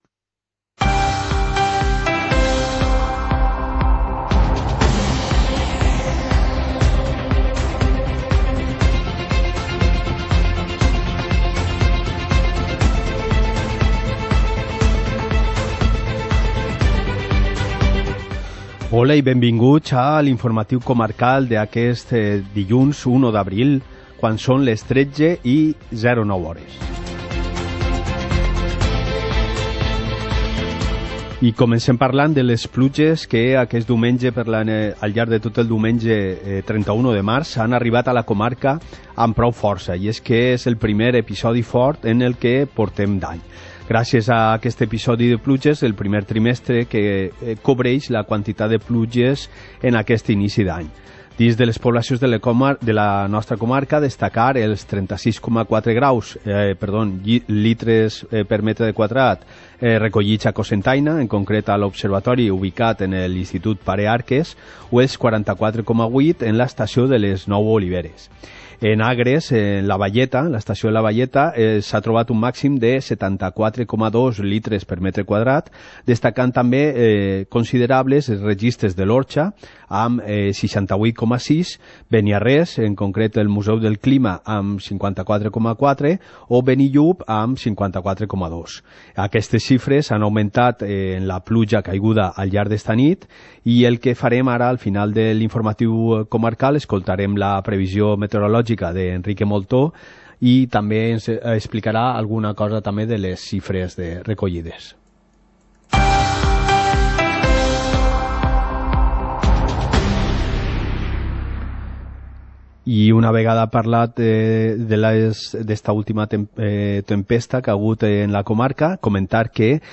Informativo comarcal - lunes, 01 de abril de 2019